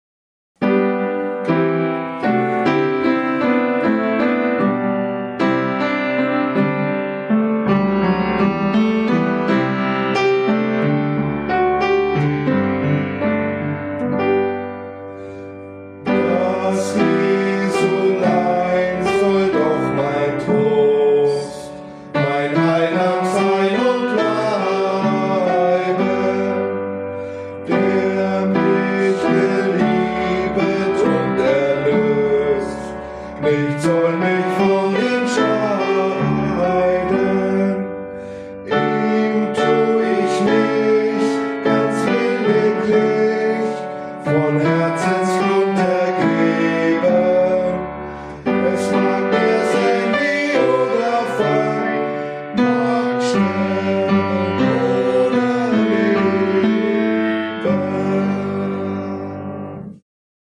Gesang, Gitarre
Klavier